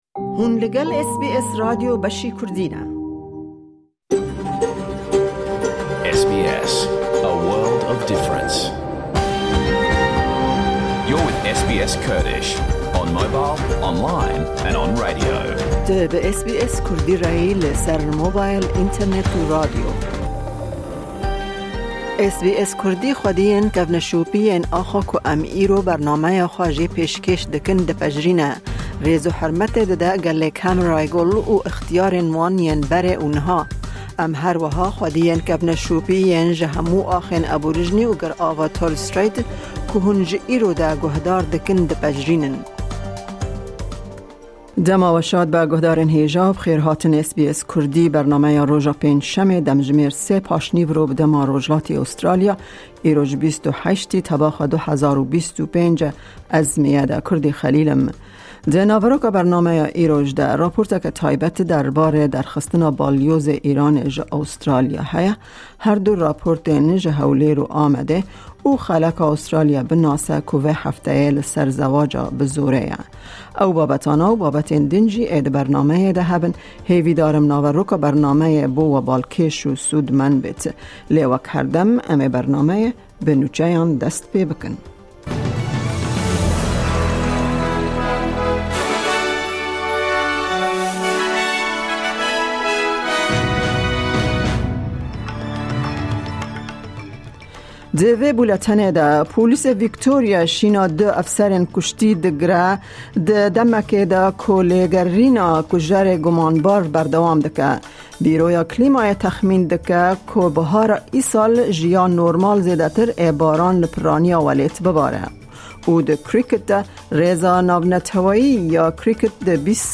Nûçe, hevpeyvîn, raporên ji Amed û Hewlêre û babetên cur bi cur tê de hene.